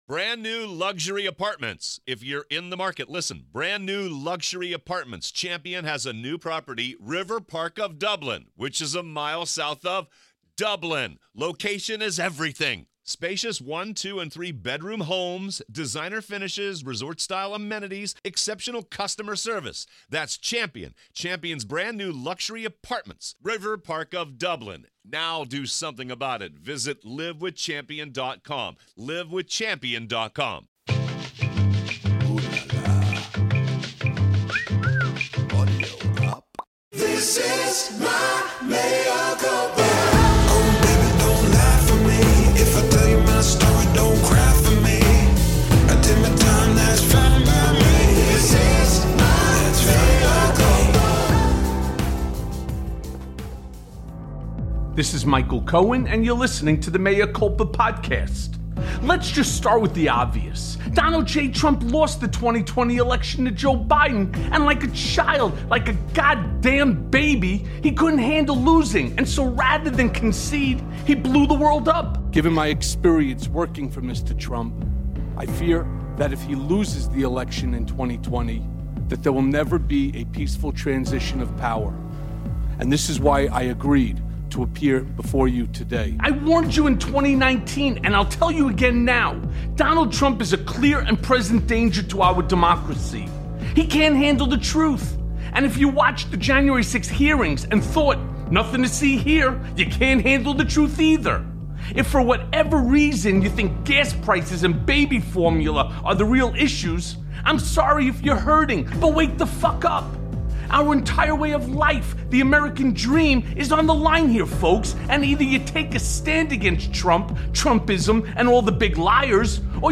January 6th Committee Has Enough Evidence to Indict Trump + A Conversation with Brian Tyler Cohen